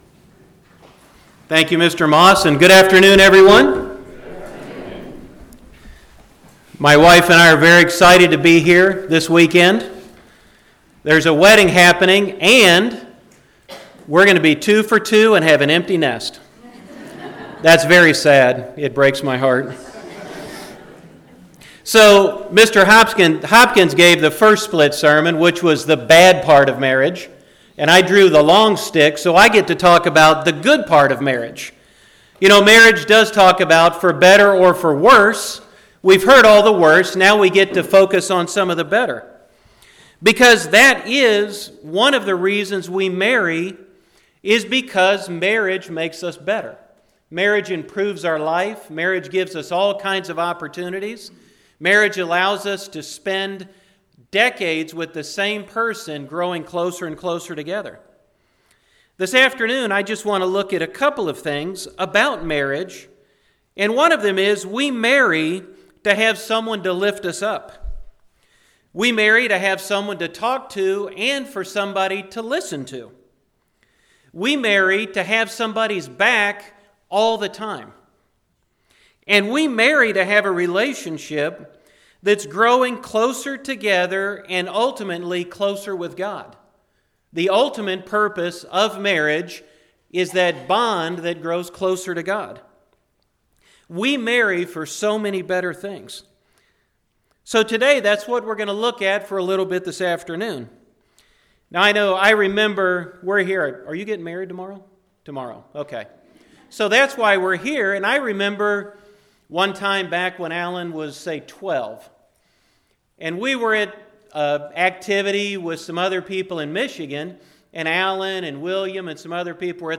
This split sermon covers the "better" part of marriage in "for better or worse".